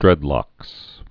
(drĕdlŏks)